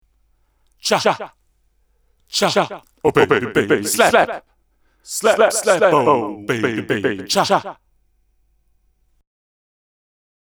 Tältä kaiut ja viiveet kuulostavat (muutin viiveajat Tap Tempo -toiminnalla):
Slap
slap.mp3